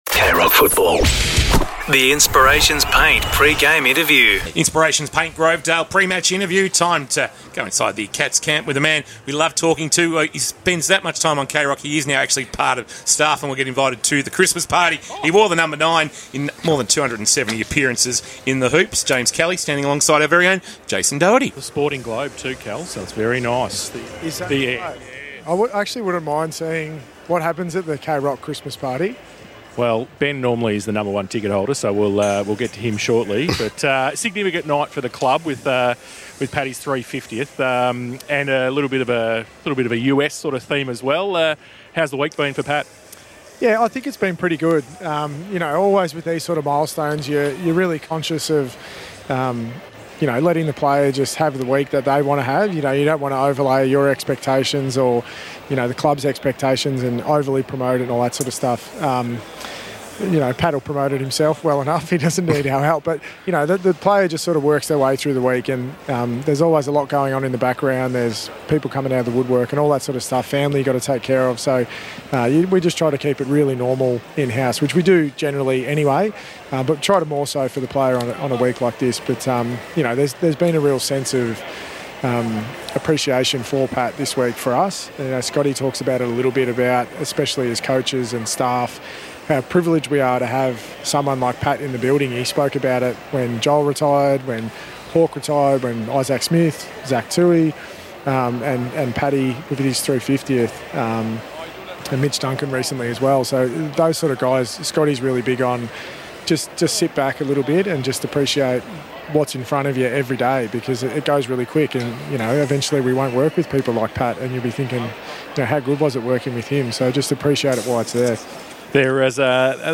2025 - AFL - Round 15 - Geelong vs. Brisbane: Pre-match interview